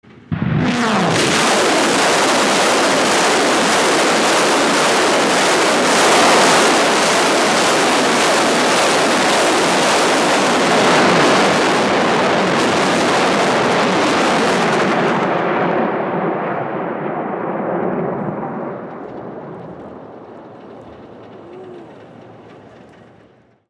На этой странице собраны исторические записи звуков залпов «Катюши» — легендарной реактивной системы, ставшей символом победы в Великой Отечественной войне.
Грозный звук стрельбы Катюши (БМ-8) – легендарной ракетной артиллерийской установки